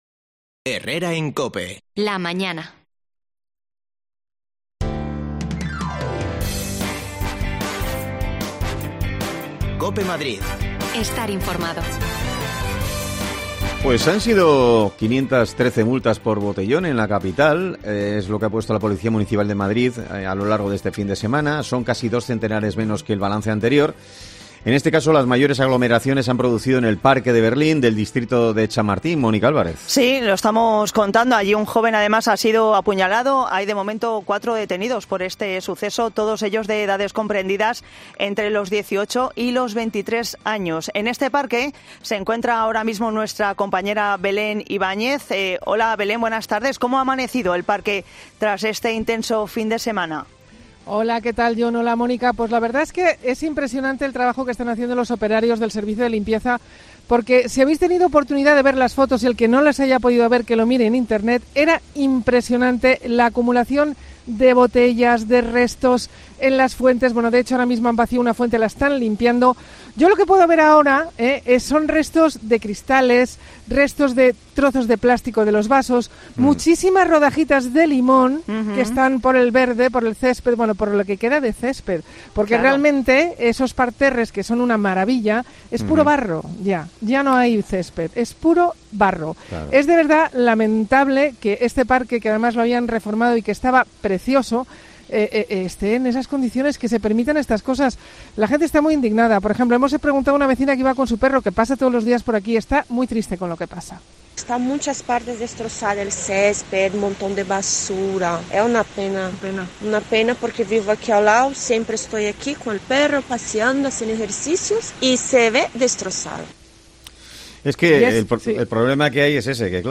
El Parque de Berlín continúa hoy sucio y con desperfectos tras el paso del macrobotellón que concentró a cerca de 1.000 jóvenes el pasado sábado. Te lo contamos desde allí
Las desconexiones locales de Madrid son espacios de 10 minutos de duración que se emiten en COPE , de lunes a viernes.